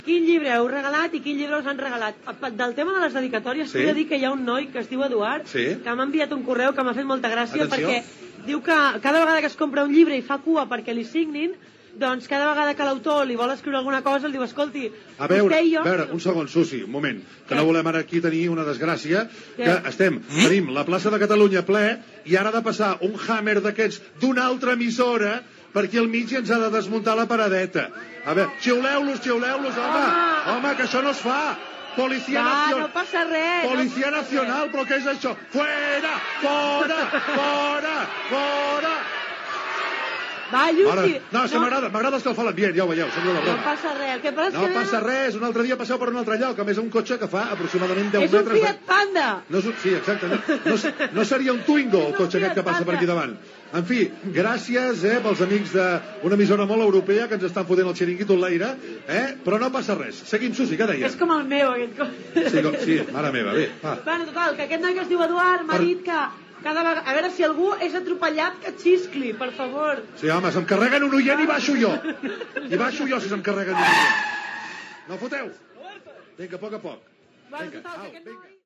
Narració del pas del Hummer Limusina d'Europa FM davant del camió de Catalunya Ràdio, a la Plaça Catalunya de Barcelona, el dia de Sant Jordi
Entreteniment